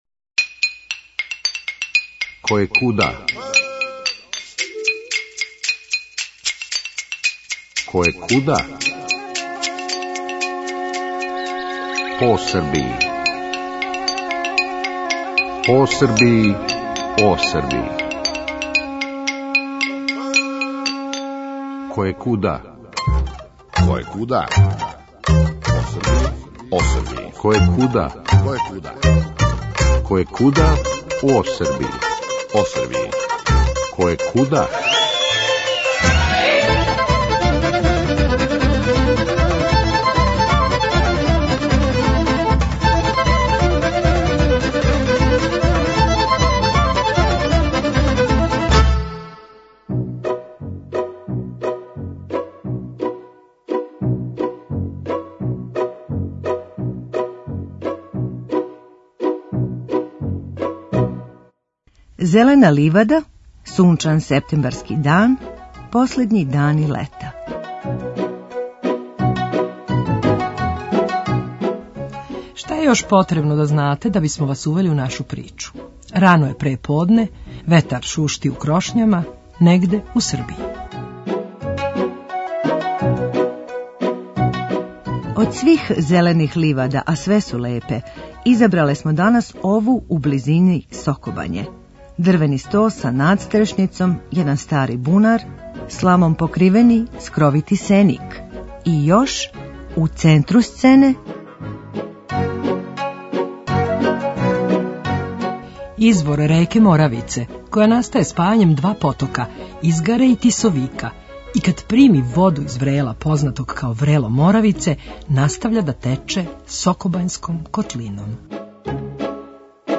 Зелена ливада, сунчан септембарски дан, последњи дани лета… Шта је још потребно да знате да бисмо вас увели у нашу причу – рано је преподне, ветар шушти у крошњама...негде у Србији.
Од свих зелених ливада, а све су лепе, изабрале смо данас ову у близини Сокобање.